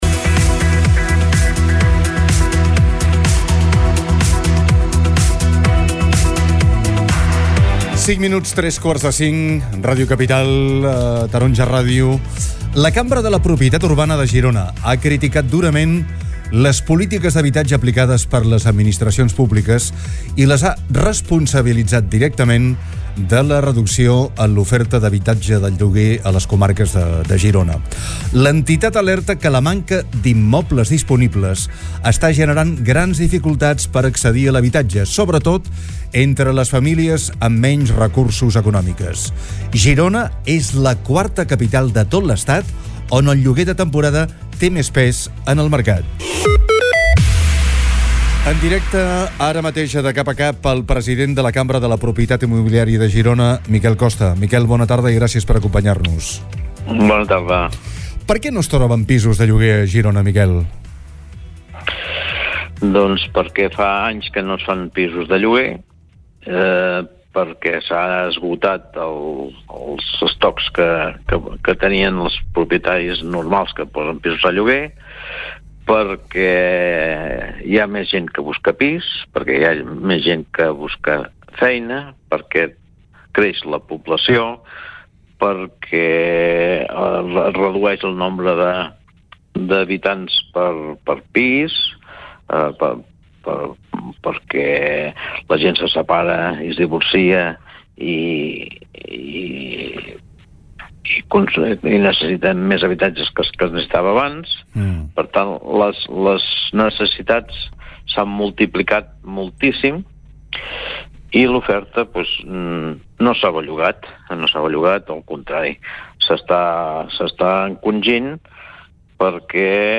entrevistat